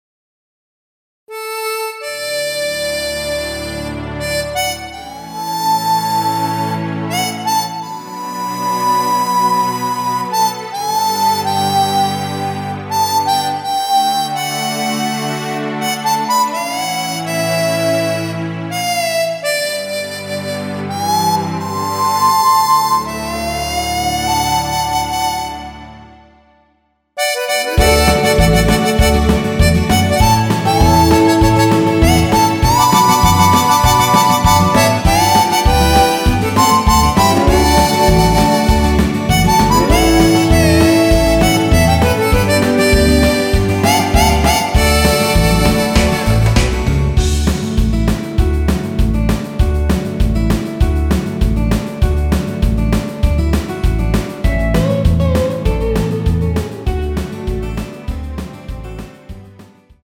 원키에서(+2)올린 MR입니다.
앞부분30초, 뒷부분30초씩 편집해서 올려 드리고 있습니다.
중간에 음이 끈어지고 다시 나오는 이유는
곡명 옆 (-1)은 반음 내림, (+1)은 반음 올림 입니다.